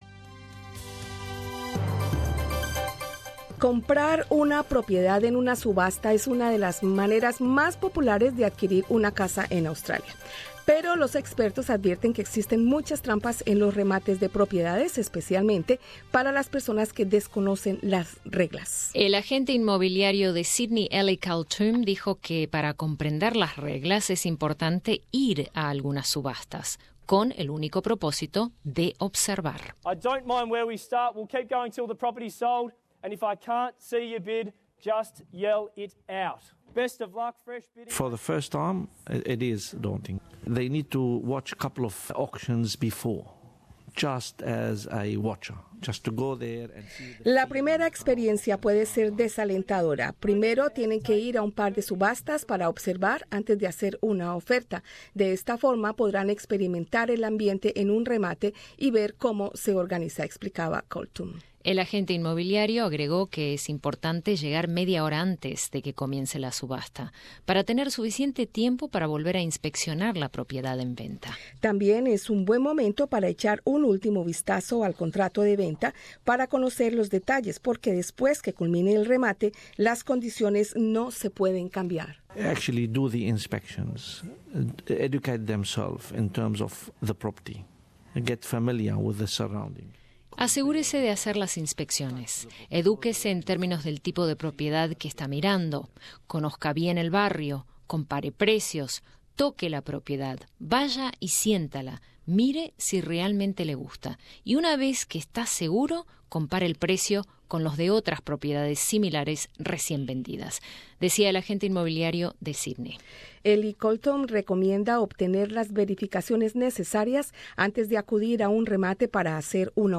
Un agente inmobiliario y dos compradores comparten consejos para comprar una casa en remate en Australia.